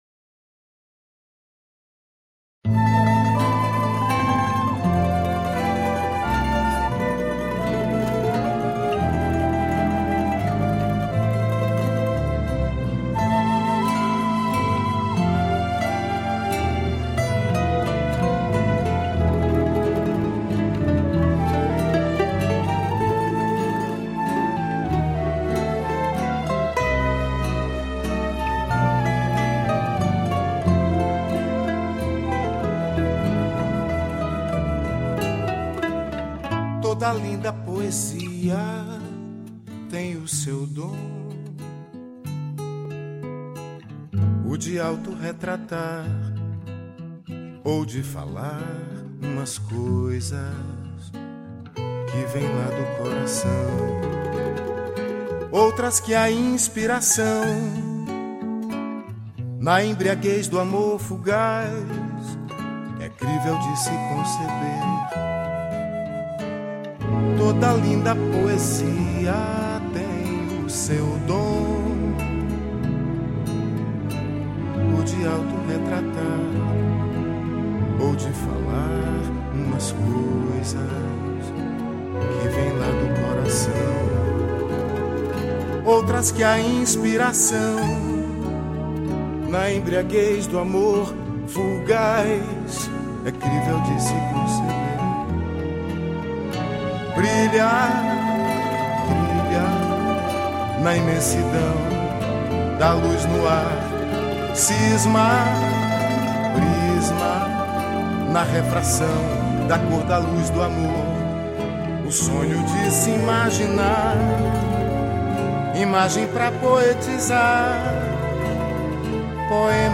6   04:35:00   Faixa:     Valsa
Flauta
Bandolim
Violao Acústico 6
Piano Acústico, Teclados